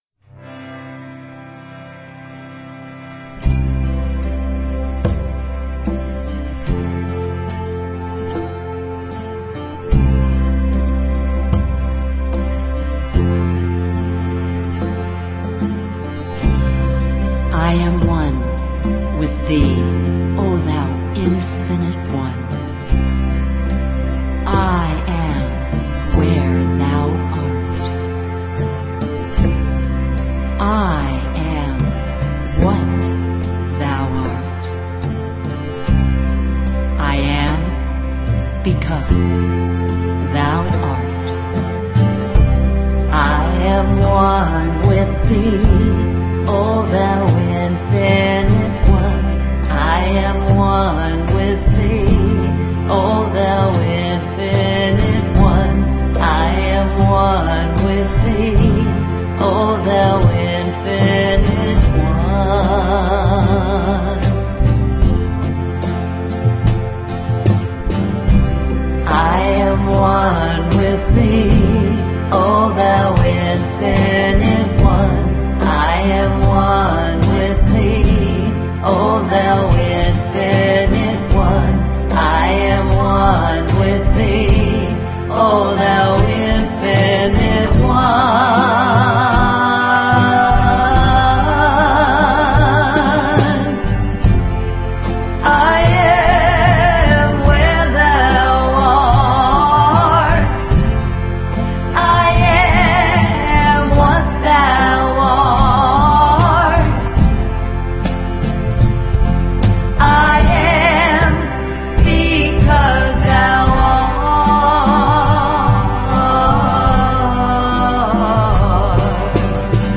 1. Devotional Songs
Major (Shankarabharanam / Bilawal)
8 Beat / Keherwa / Adi
Medium Slow